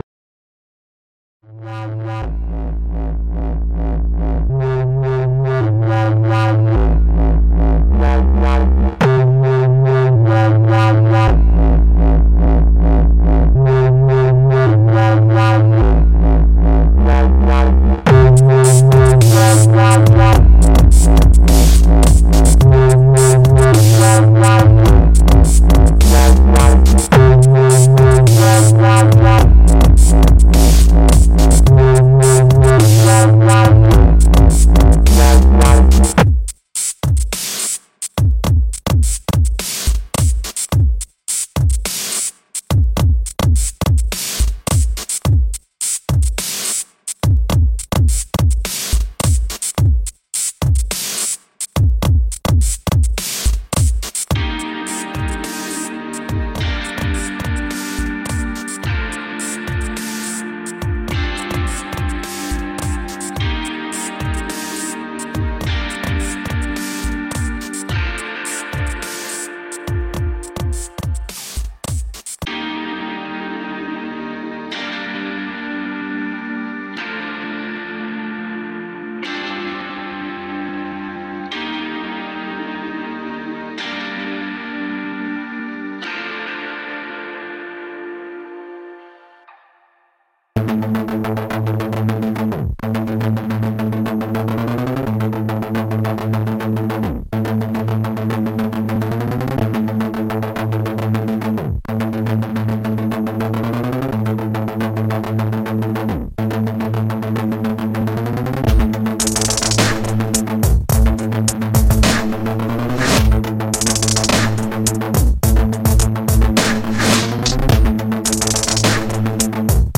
У композиции нет текста